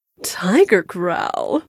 tiger-growl_spoken
Category: Games   Right: Personal